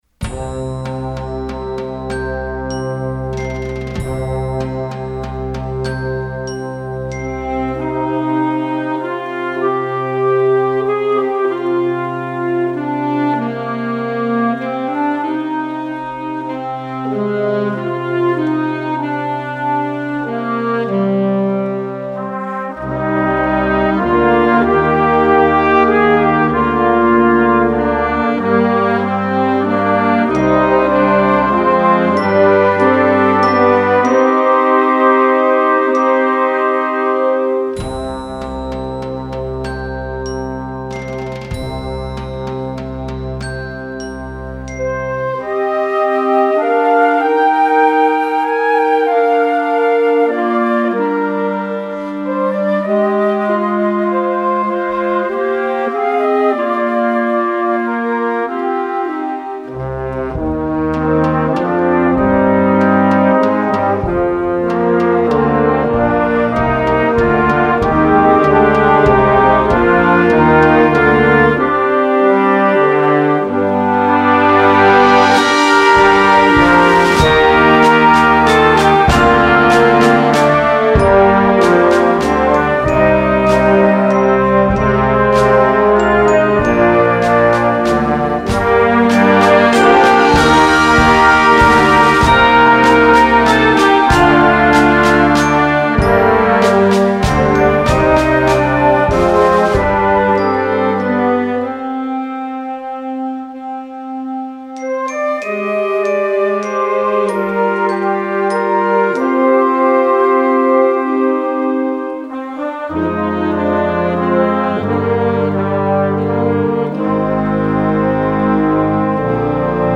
Gattung: Blasmusik für Jugendkapelle - Performer Level
Besetzung: Blasorchester
die sehnsuchtsvolle Melodie mit ihren modalen Harmonien
für Anfängerensemble besetzt